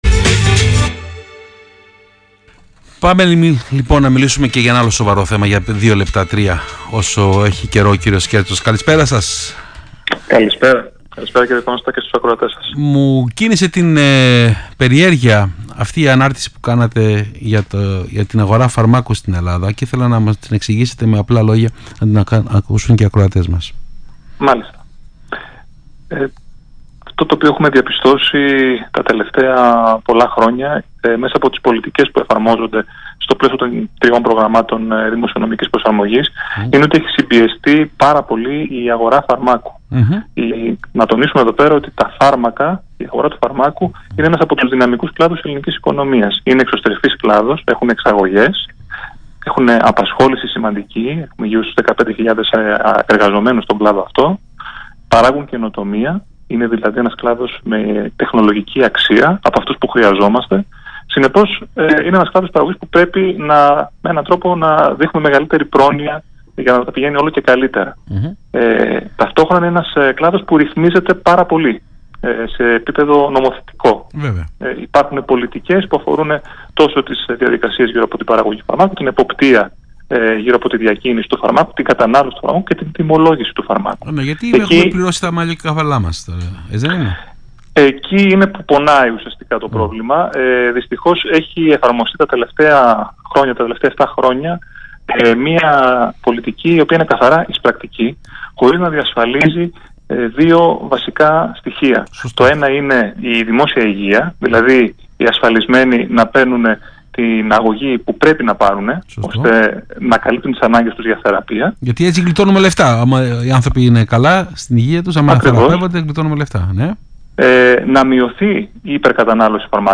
Συνέντευξη του Γενικού Διευθυντή του ΣΕΒ, κ. Άκη Σκέρτσου στον Ρ/Σ Action FΜ, 17/2/2017